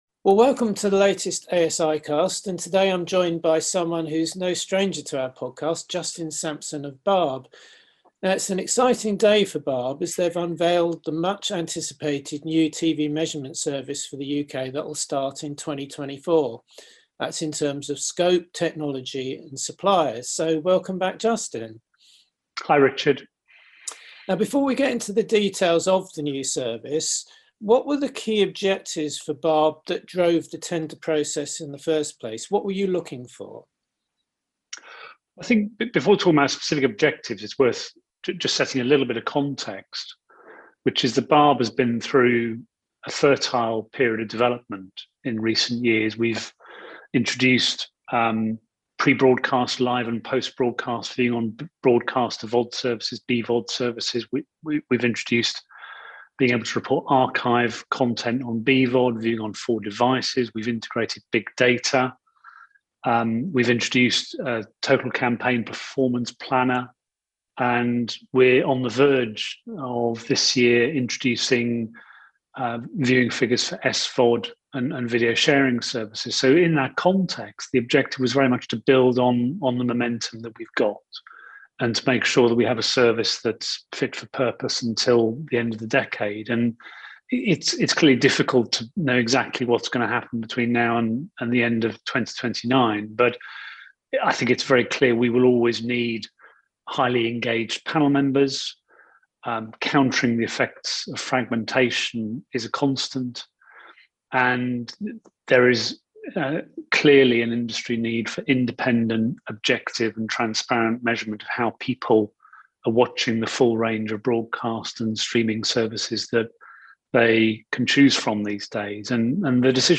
Over the last few years the service provided by BARB has been through a number of significant developments to deliver independent, objective and transparent measurement of the range of broadcast and streaming services being viewed today. In this interview